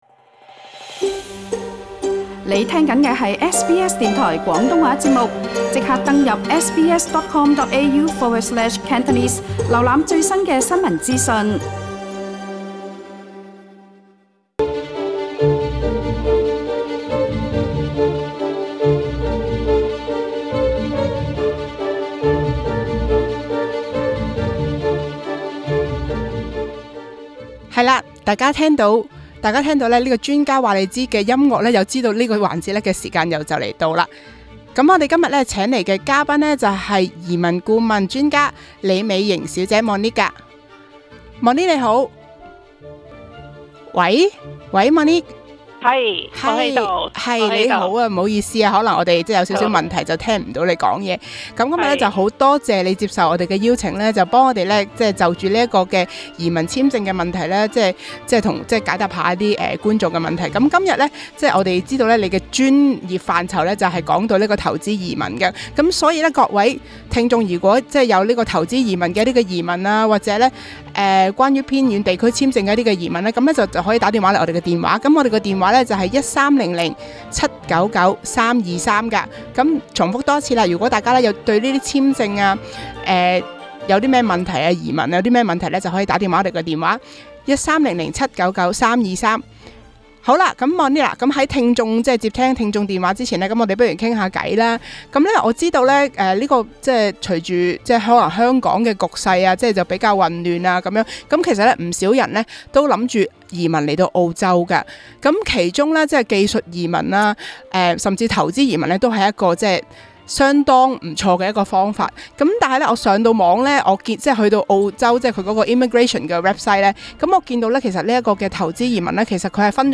今日【專家話你知】環節，移民簽證專家會同我地分享下除了技術移民外的另一個考慮──投資移民。到底是否有錢就能移民，其中有甚麼要點需注意？